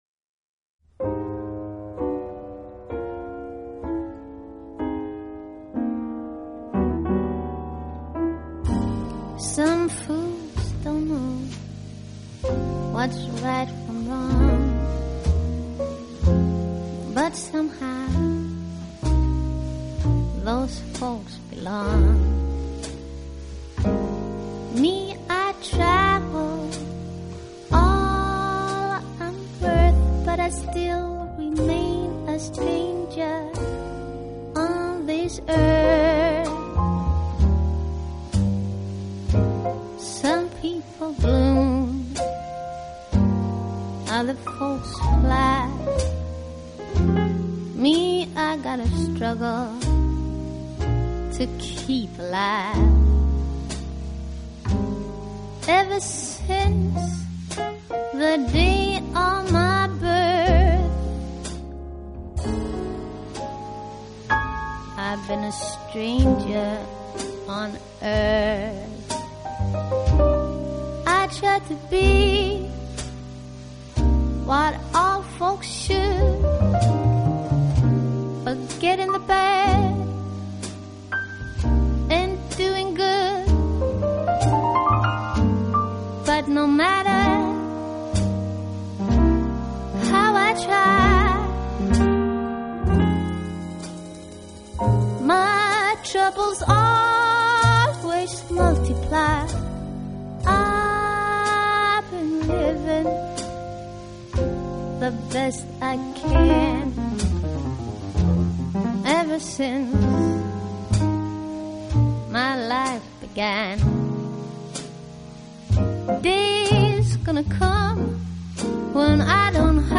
Vocal Jazz , Trad Jazz
歌声却有如雏燕般稚气，令人闻之倍感怜惜。